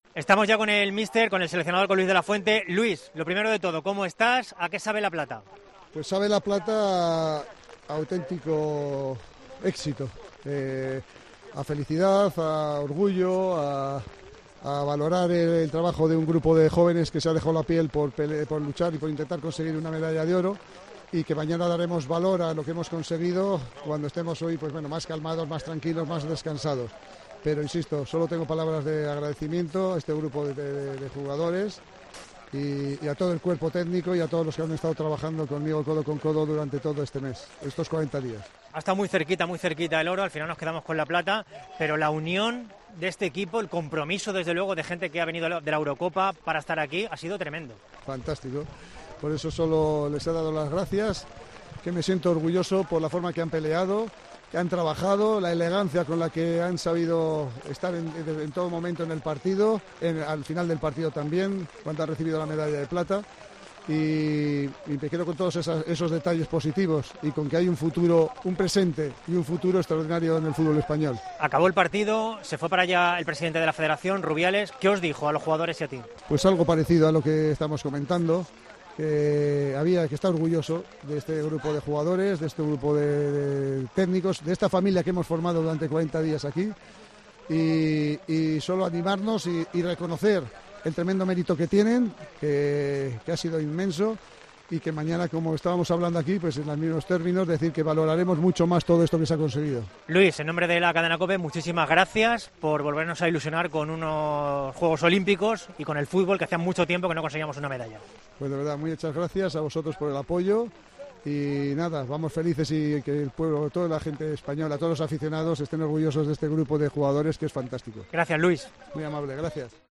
El seleccionador olímpico español de fútbol analiza en COPE la plata conseguida: "Que todos los aficionados estén orgullosos de este grupo de jugadores".
Con Paco González, Manolo Lama y Juanma Castaño